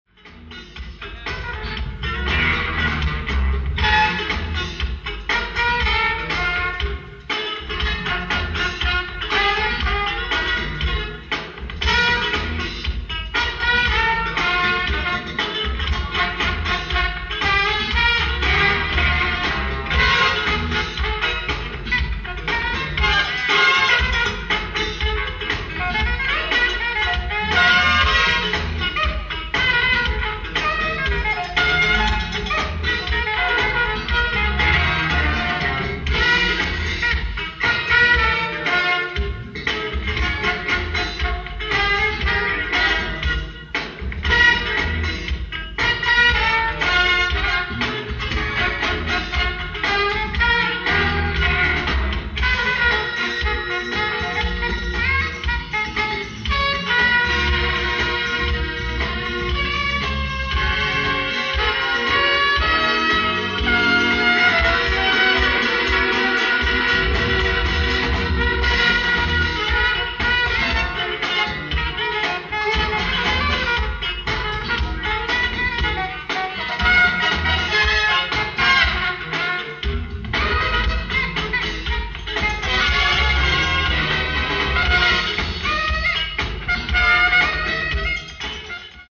Live At The Opera House, Boston, MASS September 27th, 1987
GOOD AUDIENCE RECORDING